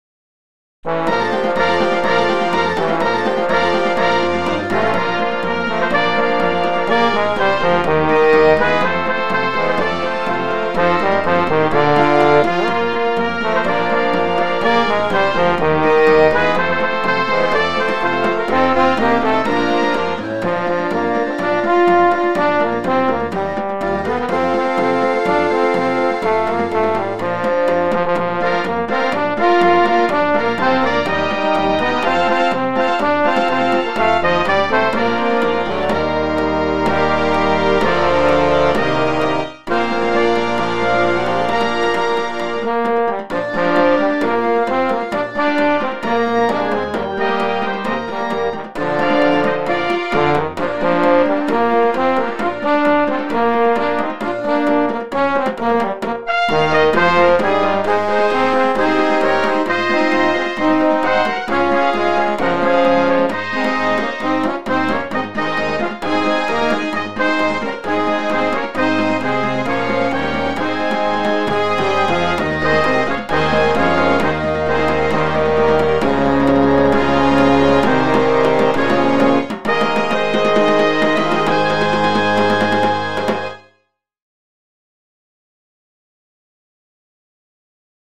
2025 Laker Marching Band Halftime Shows